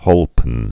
(hōlpən)